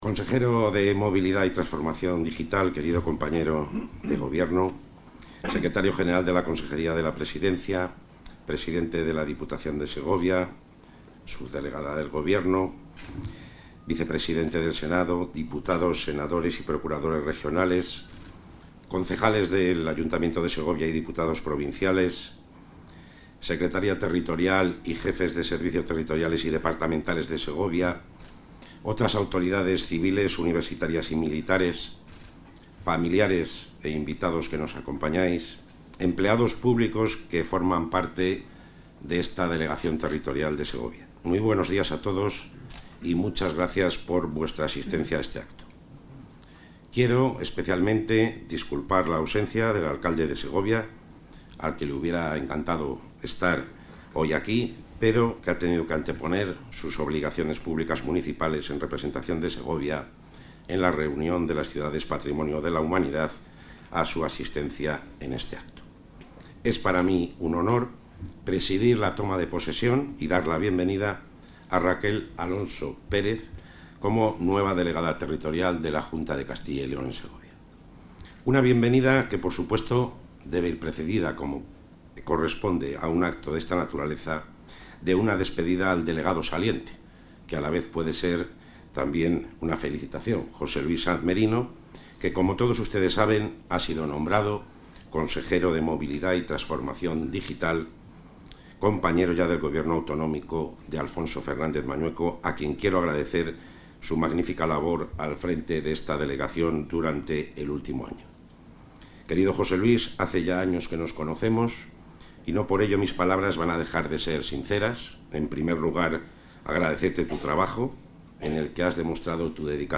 Toma de posesión de la delegada territorial de la Junta en Segovia
Intervención del consejero de la Presidencia.